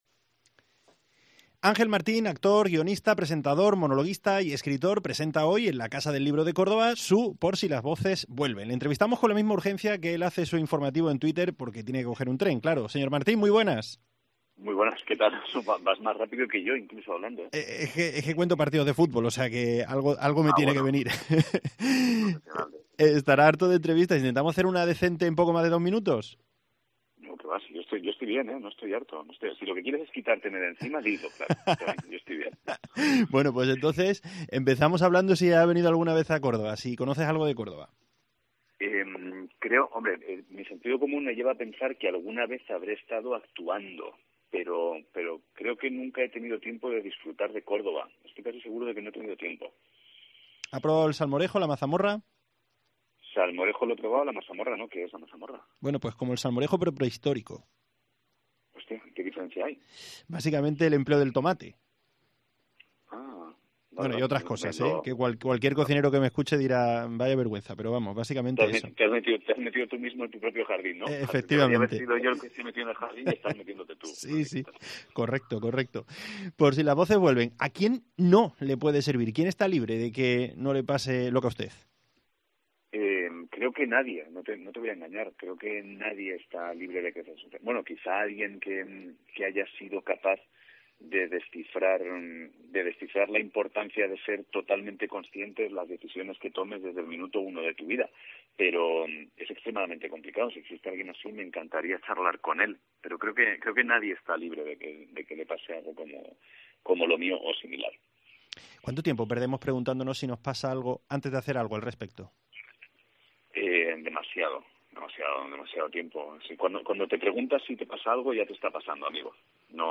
Le entrevistamos con la misma urgencia que él hace su informativo en twitter porque tiene que coger un tren, claro.